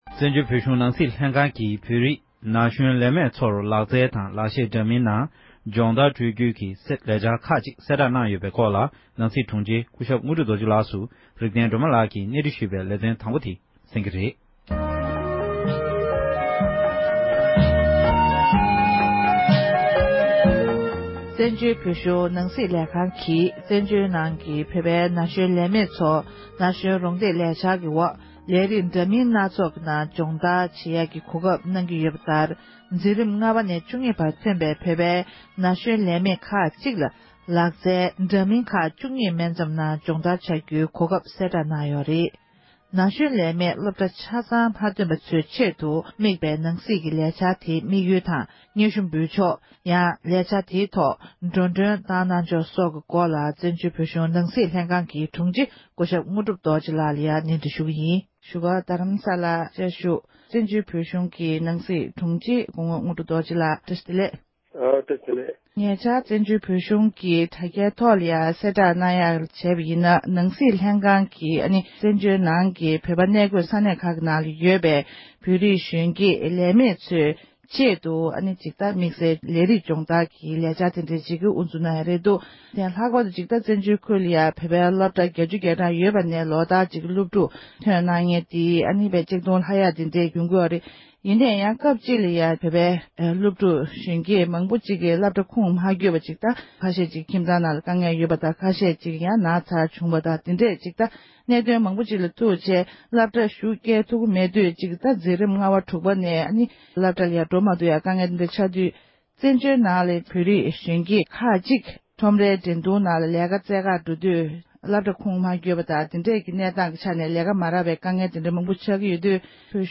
གནས་འདྲི་ཞུས་པའི་དུམ་མཚམས་དང་པོར་གསན་རོགས༎